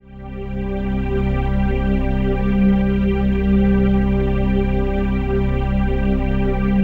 PHASEPAD04-LR.wav